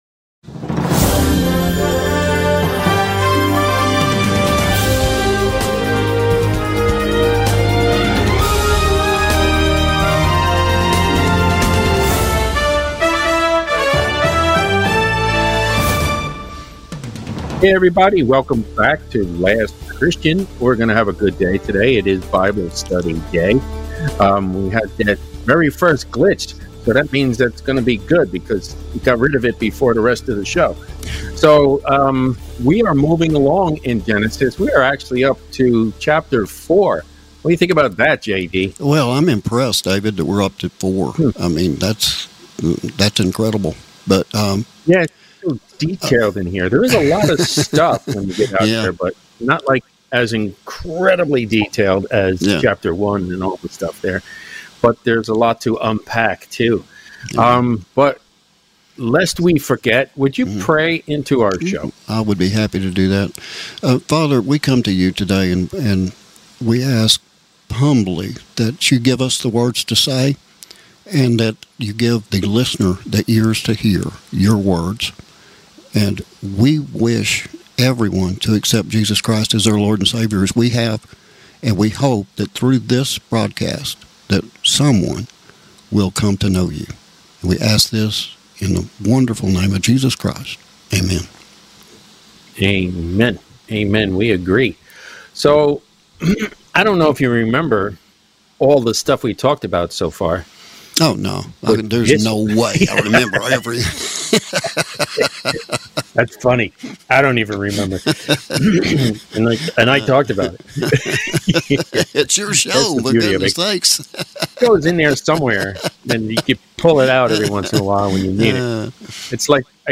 Join our Bible Study tonight as we discuss Genesis Chapter 4. The Biblical Story of Cain and his brother Abel.